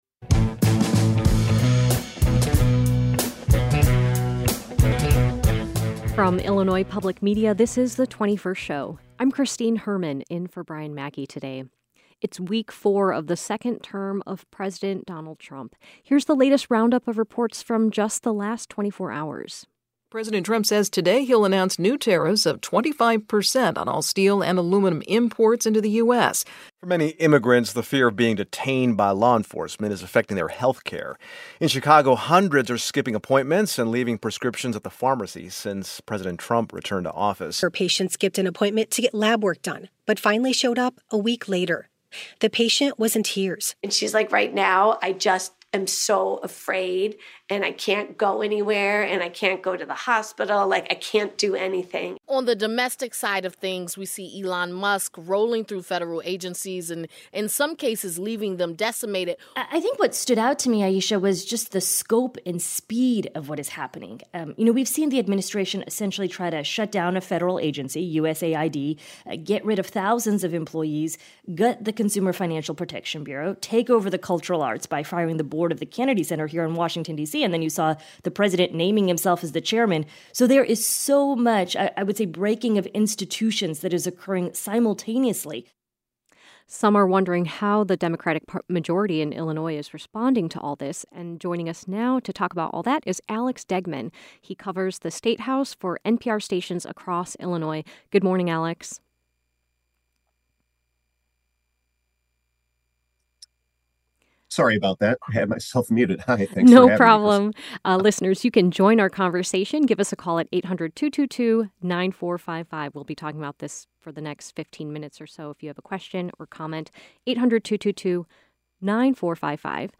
A reporter who covers the statehouse gives the latest update on the Trump administration's lawsuit against the State of Illinois and the City of Chicago and another legal battle over birthright citizenship and discusses how the state will be be impacted by tariffs on Canada and Mexico.